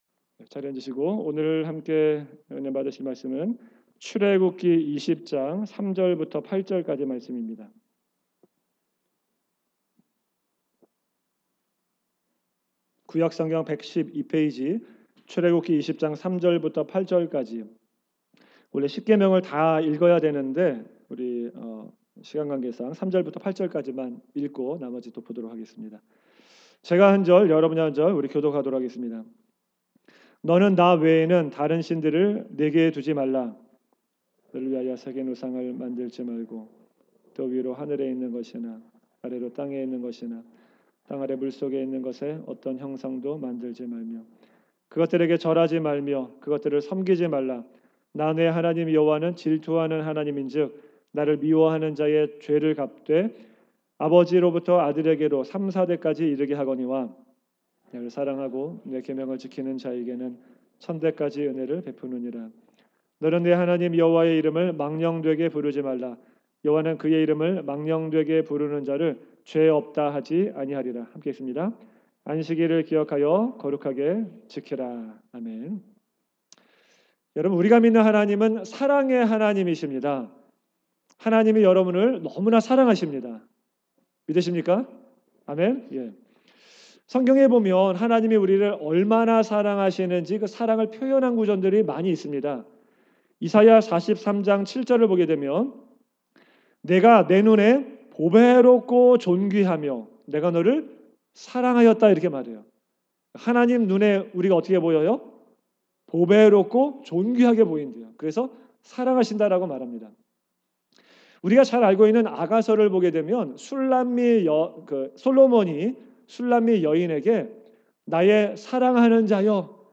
2019년 주일설교